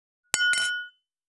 328ウイスキー,コップ,食器,テーブル,チーン,カラン,キン,コーン,チリリン,
コップ